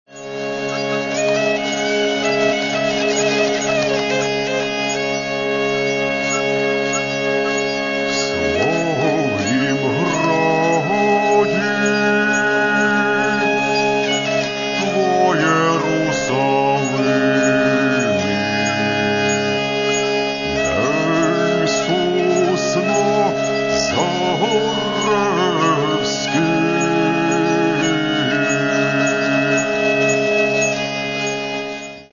Каталог -> Народная -> Бандура, кобза
псальма) – у супроводі ліри